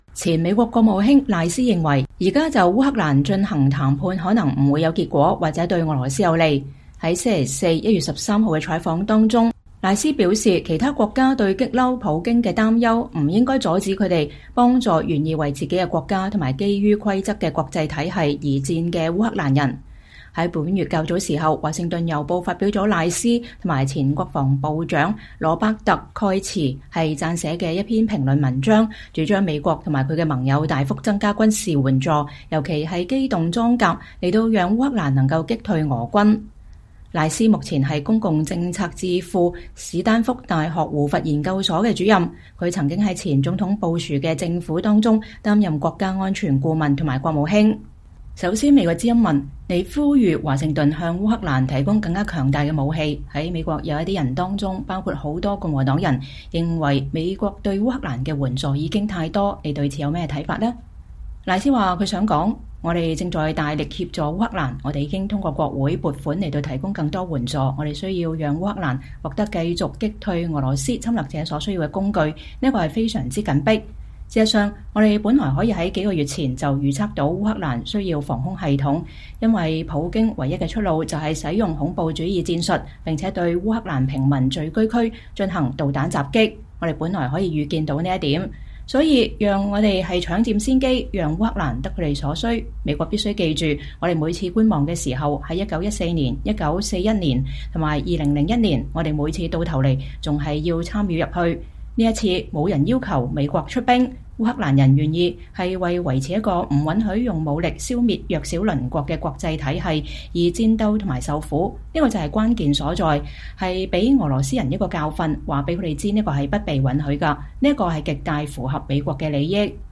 VOA專訪前國務卿賴斯：華盛頓應提供基輔所需一切軍援